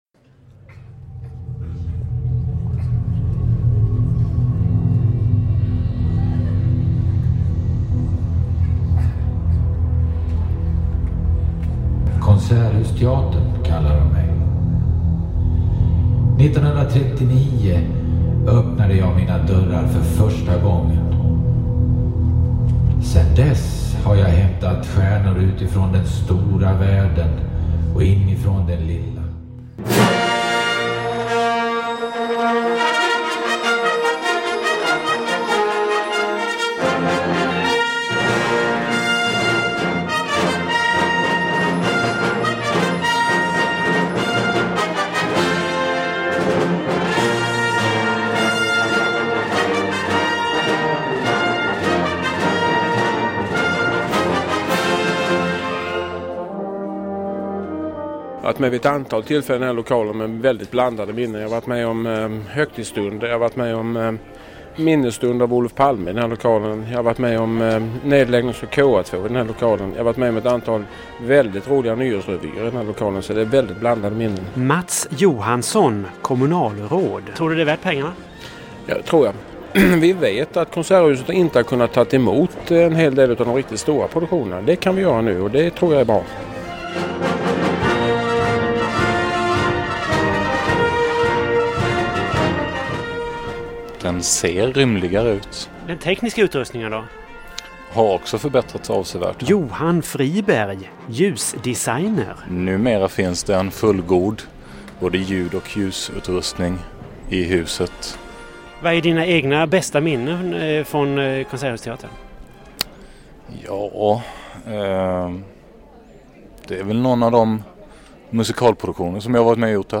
Det var efter den ombyggnaden detta radioreportage gjordes. Som en extra bonus finns hela det inspelade materialet med, cirka 40 minuter i slutet av detta poddavsnitt.